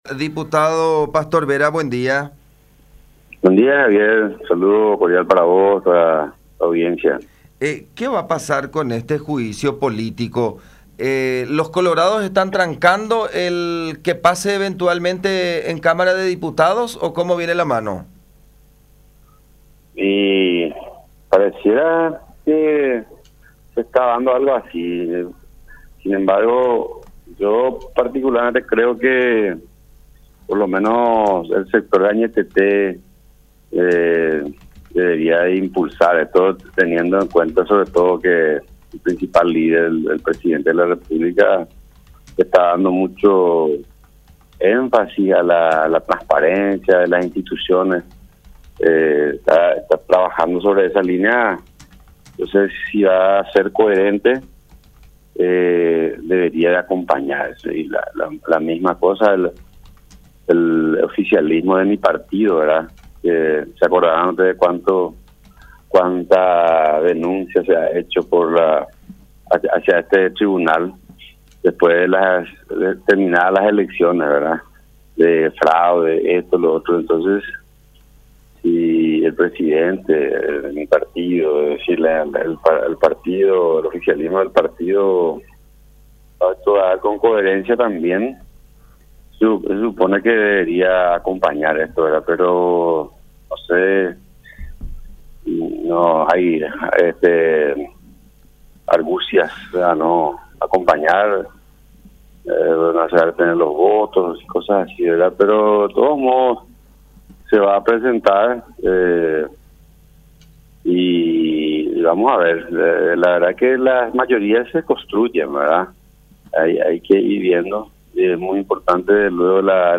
05-DIPUTADO-PASTOR-VERA.mp3